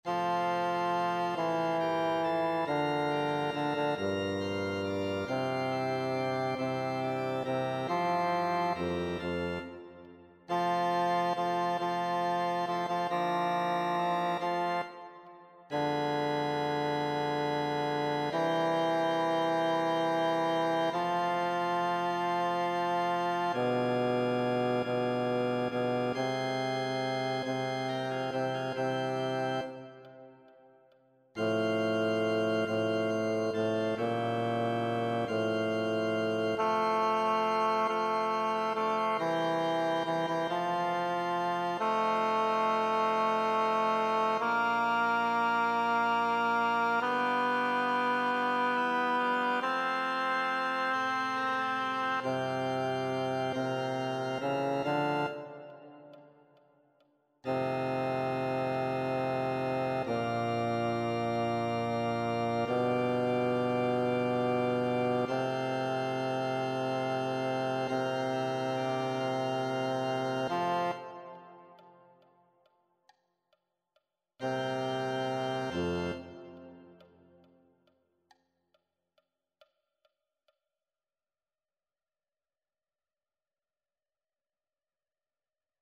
Singwoche pro Musica 2026 - Noten und Übungsdateien
Bass